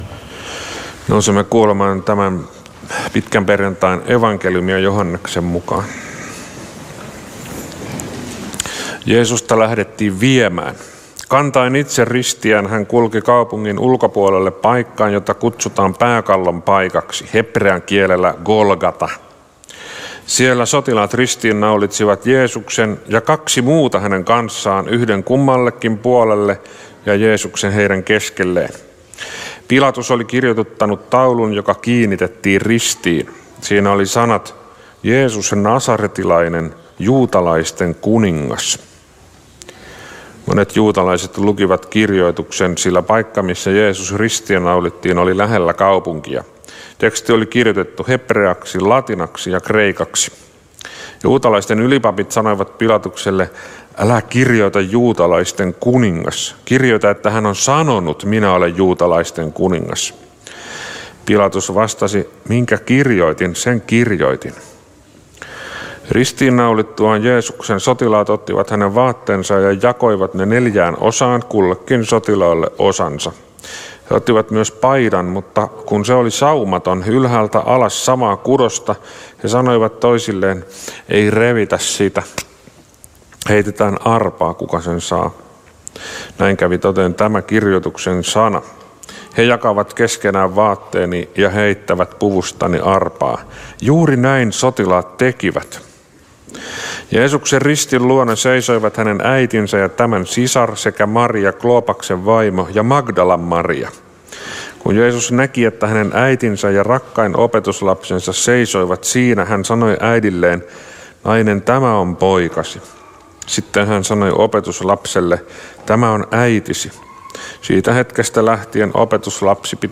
Turun Luther-kirkossa pitkäperjantaina Tekstinä Joh. 19:16–30